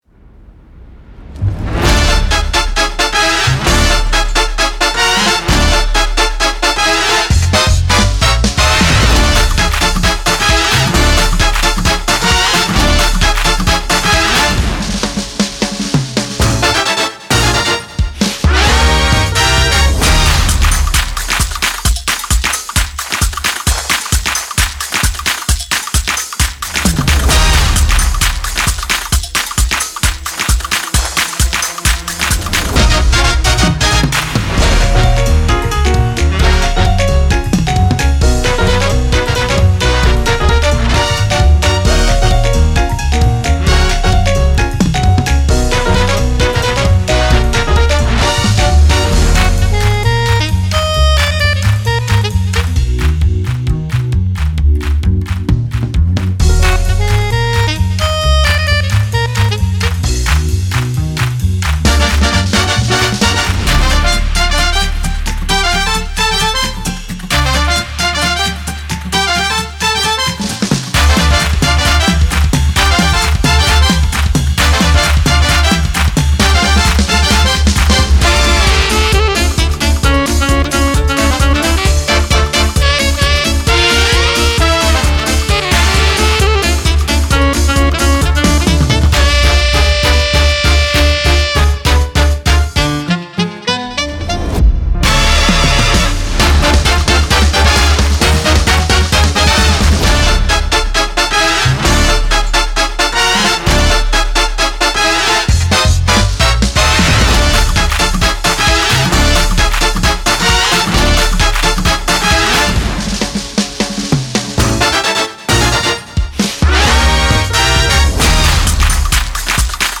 ド派手なカジノ系BGM
シネマチック, ジャズ 3:19 ダウンロード